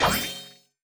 Special & Powerup (28).wav